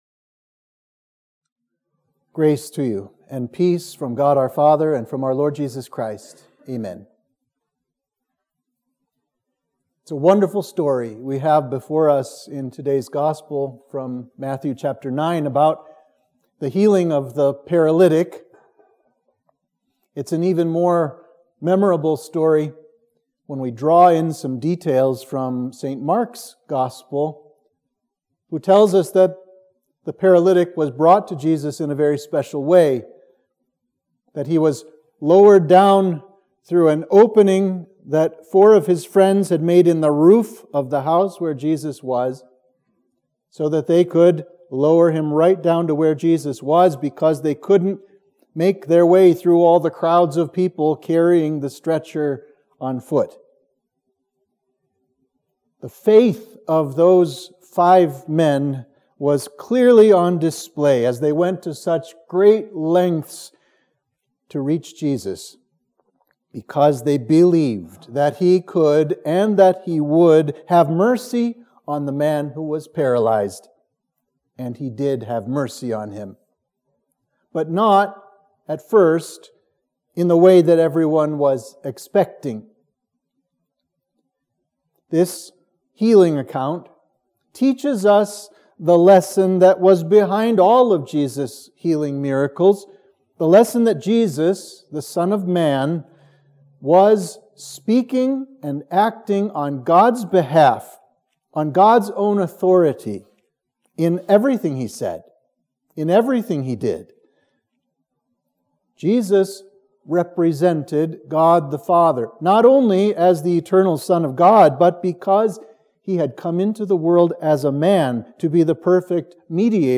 Sermon for Trinity 19